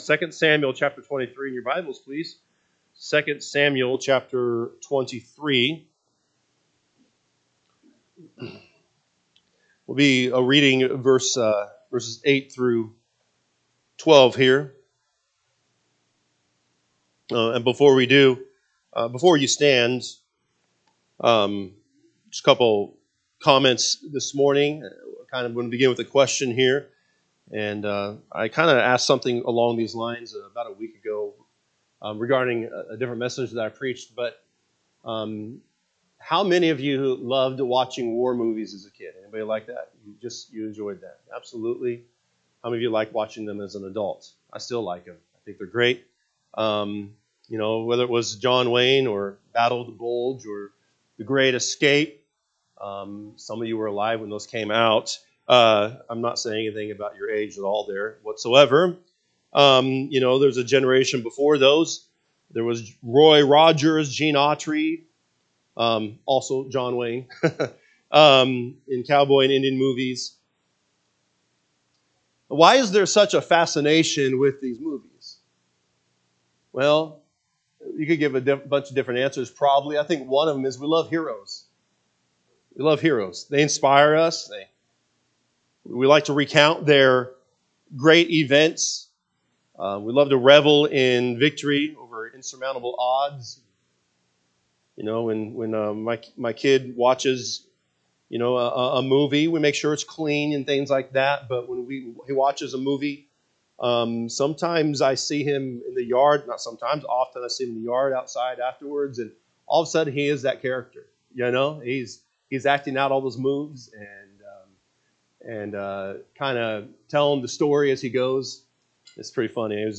May 25, 2025 am Service 2 Samuel 23:8-17 (KJB) 8 These be the names of the mighty men whom David had: The Tachmonite that sat in the seat, chief among the captains; the same was Adino the Ezni…